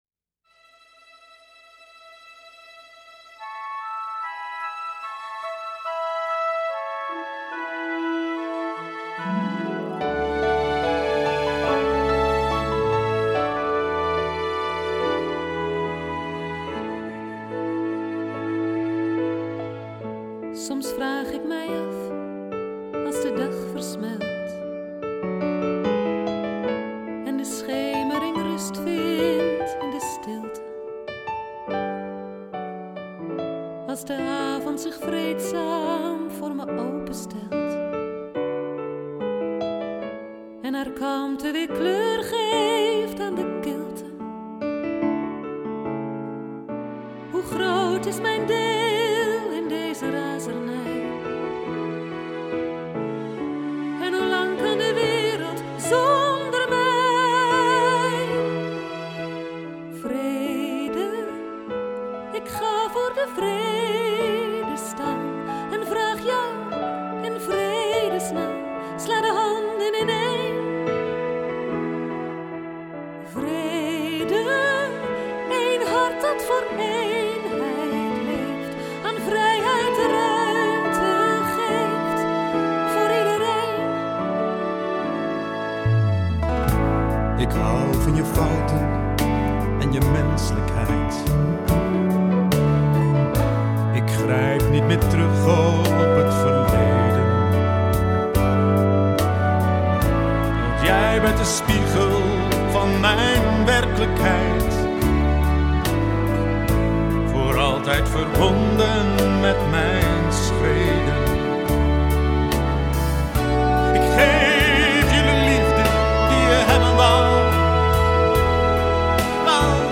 To Be formatie zong het lied van de
Vrede en de Vrijheid bij de Volksopstand 2008!